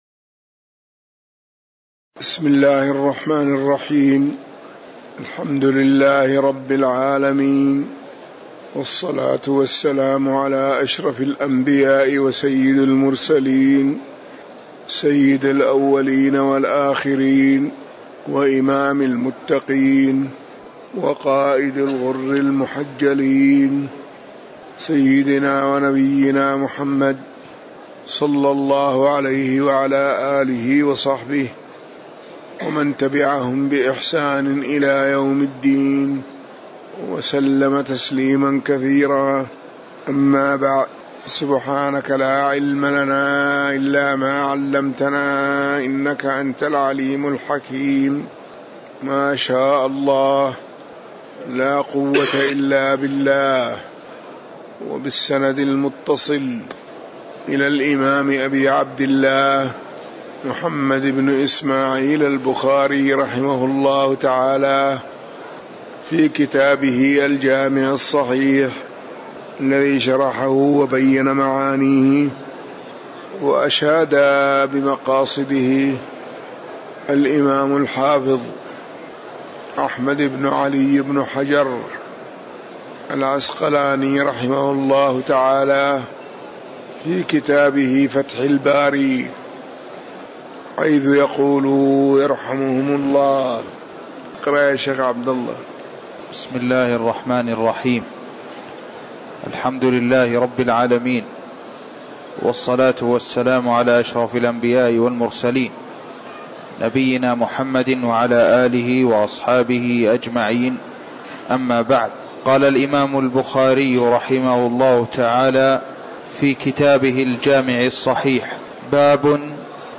تاريخ النشر ٢٨ ربيع الثاني ١٤٤١ هـ المكان: المسجد النبوي الشيخ